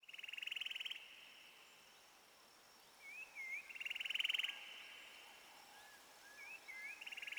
TLC - Birds (FX).wav